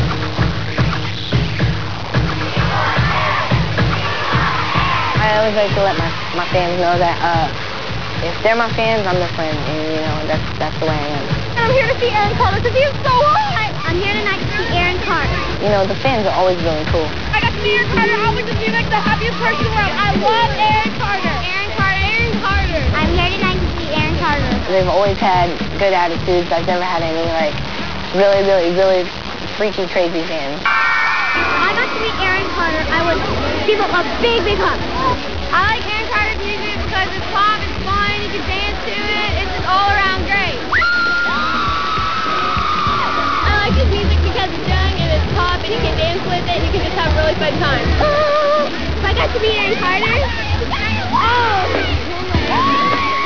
Sounds Recorded From TV Shows
I apologize for the quality of the sounds as I was not able to directly line-in record them, so they are slightly fuzzy
Fanz talking about their admiration of Aaron